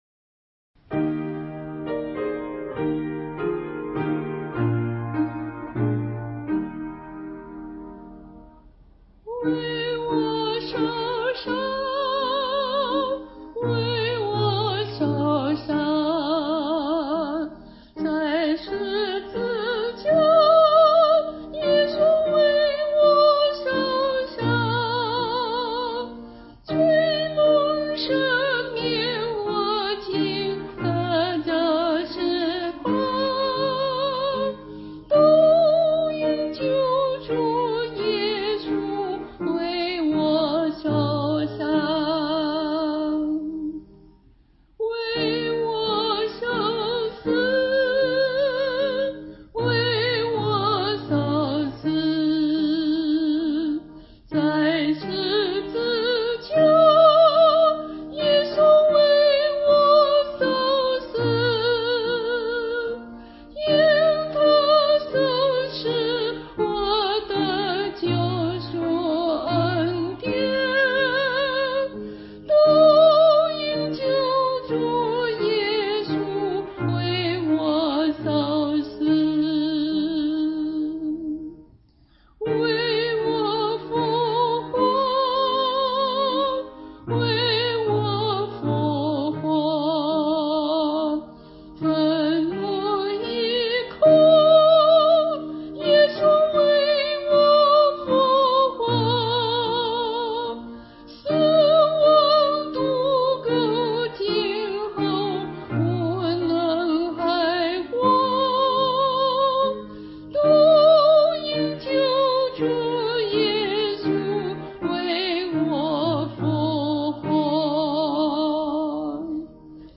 伴奏
它旋律优美，充满激情，是最著名的福音诗歌之一。